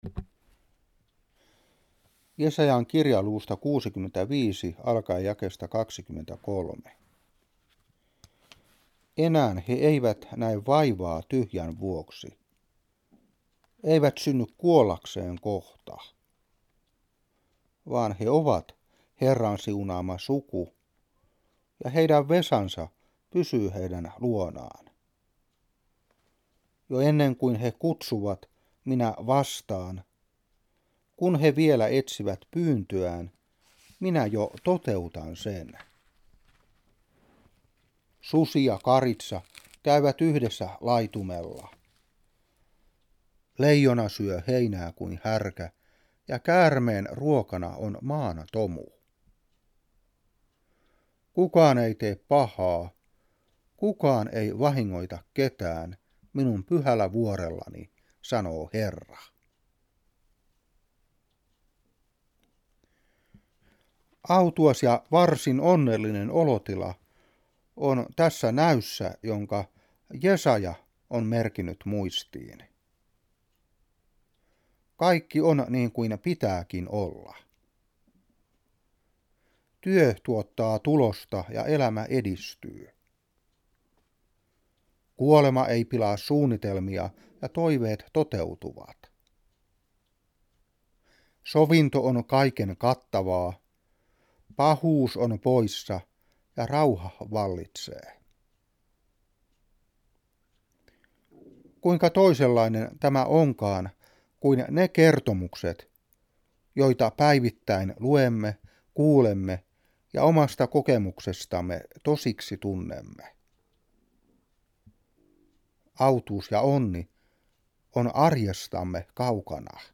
Seurapuhe 2003-11.